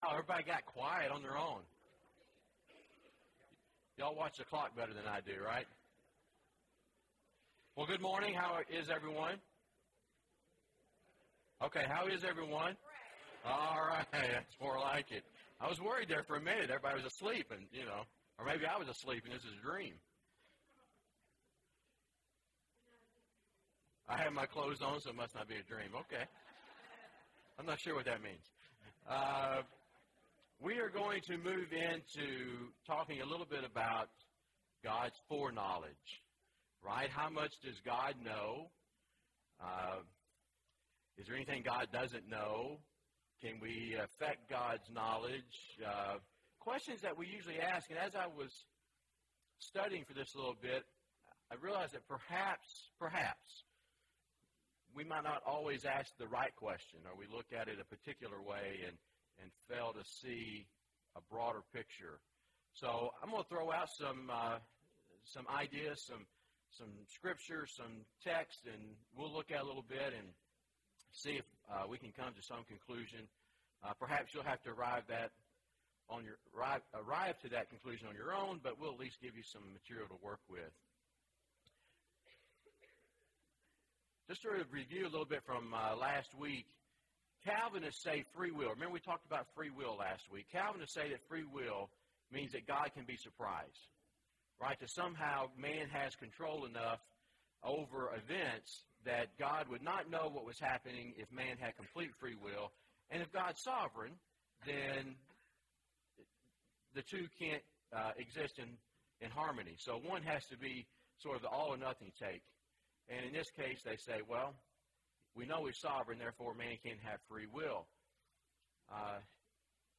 Man’s Free Will and God’s Foreknowledge (5 of 13) – Bible Lesson Recording